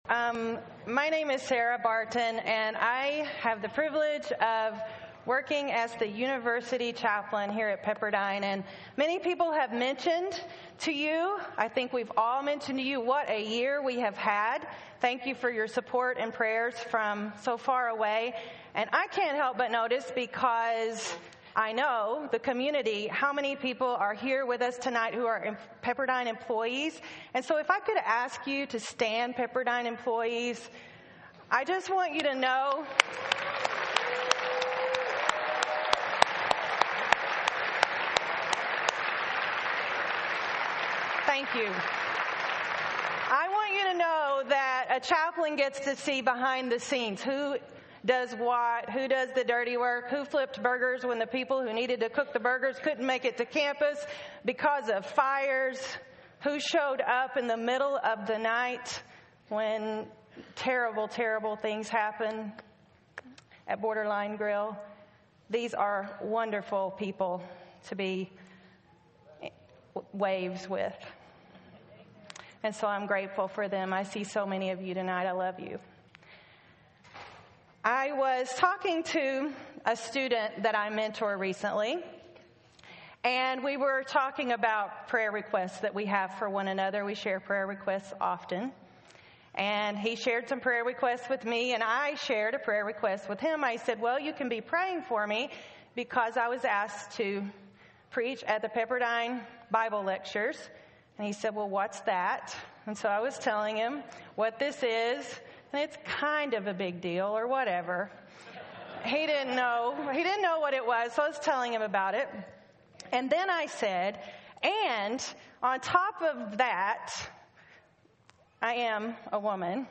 Keynote: David and Bathsheba